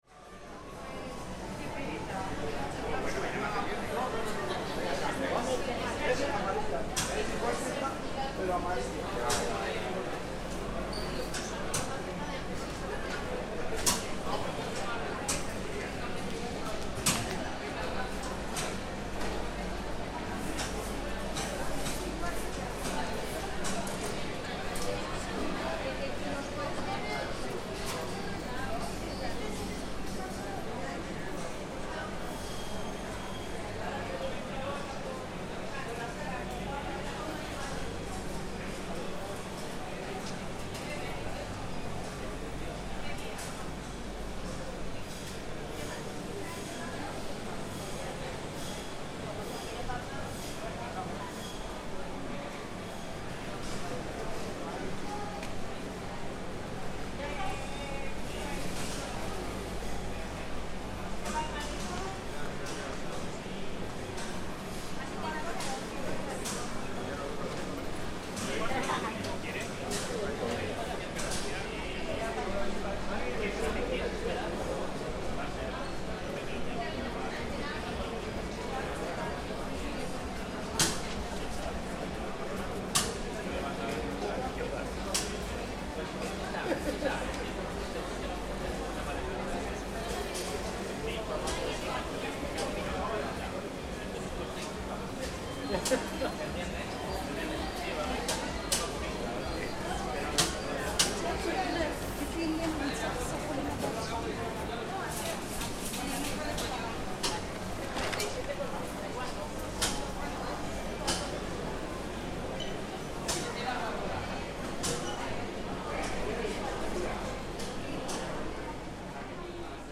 Sound recording of the Central Market of Zaragoza, a historic and unique building where accents and languages mix.